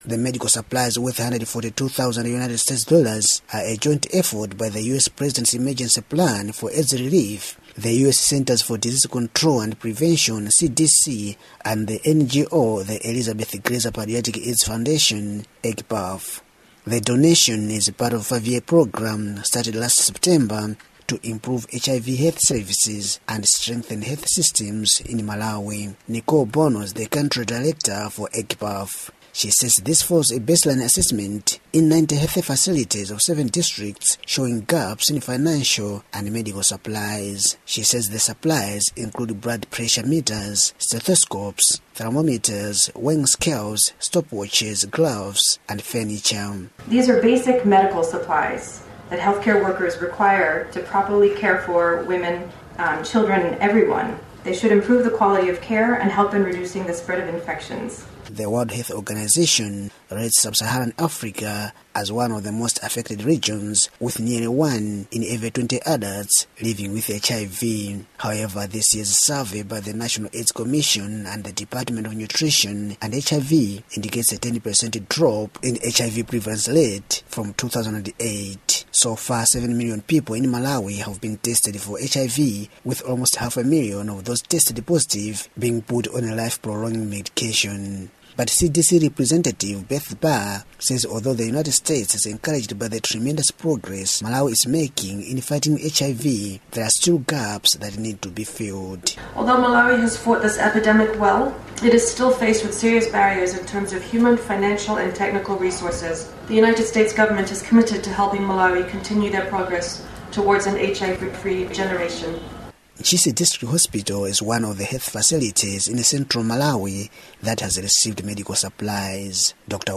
Listen to report on project to provide medical supplies for treating AIDS in Malawi